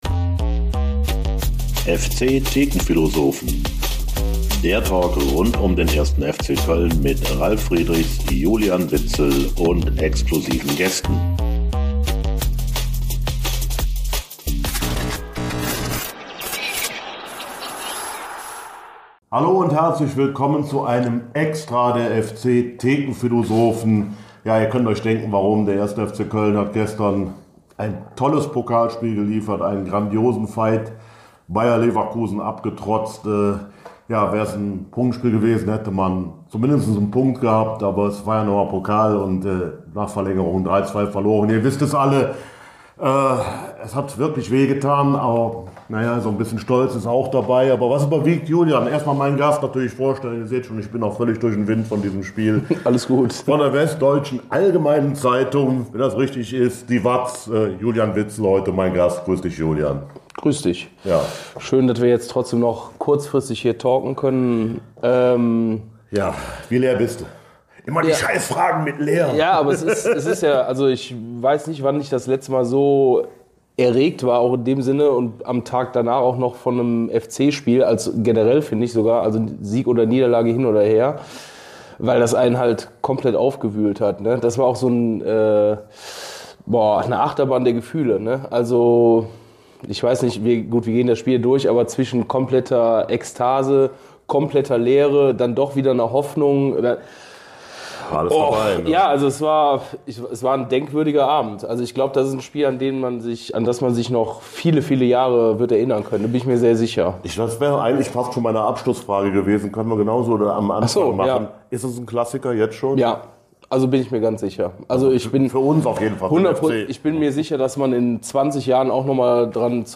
1. FC Köln verliert unglücklich in Leverkusen - Neuer "FC-Pokal-Klassiker“? Thekenphilosophen-EXTRA - Folge 80 ~ FC-Thekenphilosophen - Der Talk Podcast